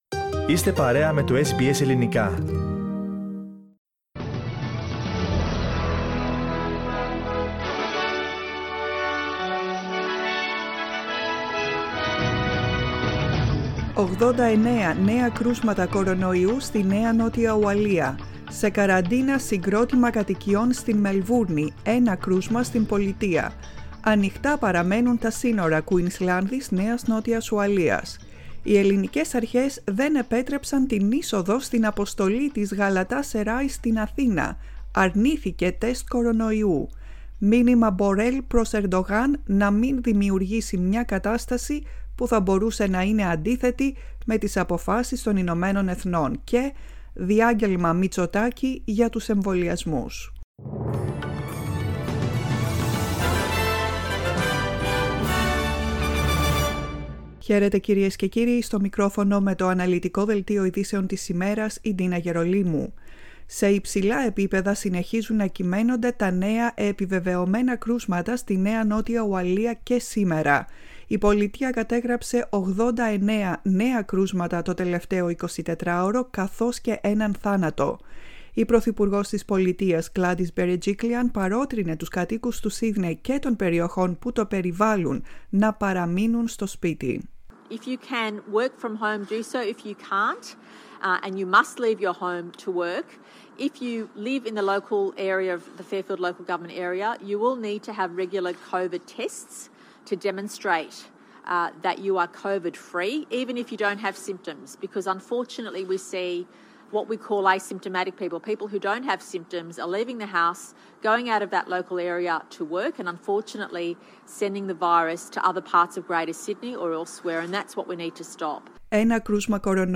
Listen to the Greek Language Program's main news bulletin.